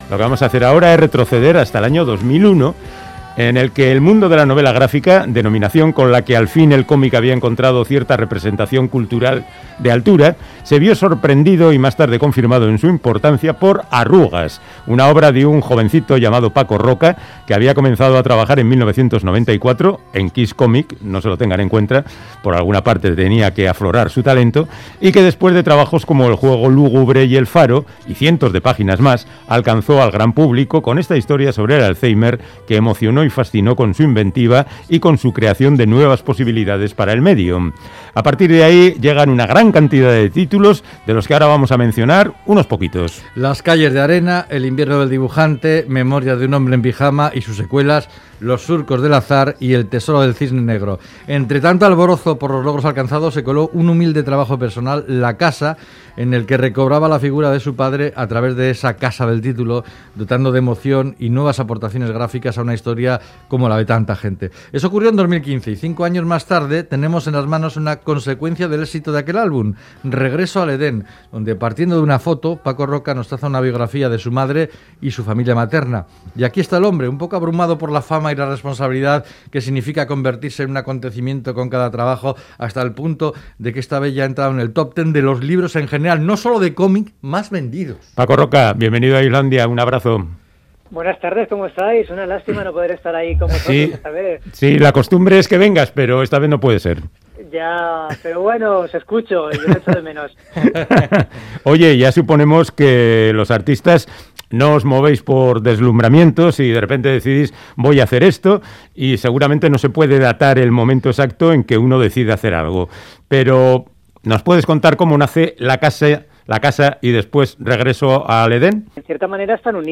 Audio: Charlamos con el guionista y dibujante de cómic valenciano Paco Roca sobre su novela gráfica "Regreso al Edén", el retrato de la vida de su madre